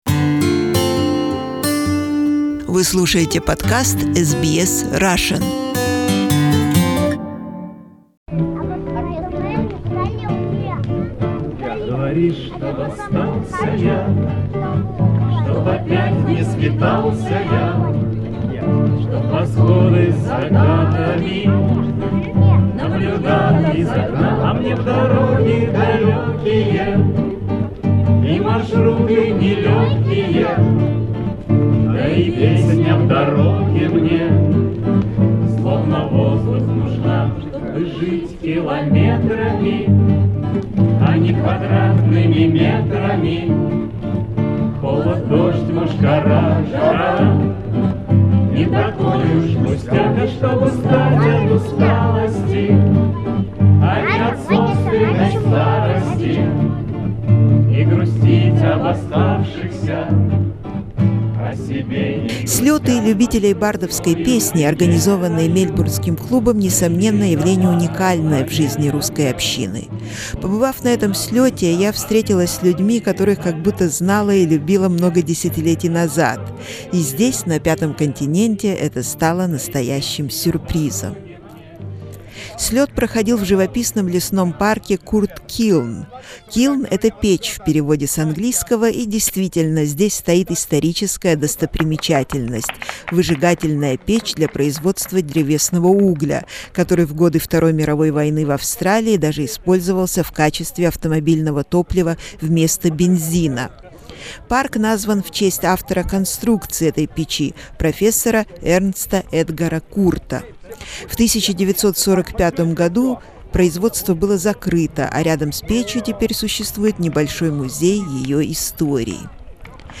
Fun and songs at the Melbourne bard club camping: early days in 2006
Its annual music festivals attract lots of fans and whole families are singing and camping together. SBS Russian program has always tried to cover this big event of the club, but only ones actually we had the opportunity to be there ourselves and take part in singing our favourite songs around bonfire.
Melbourne Southern Cross singing club camping, March 2006 Source: SBS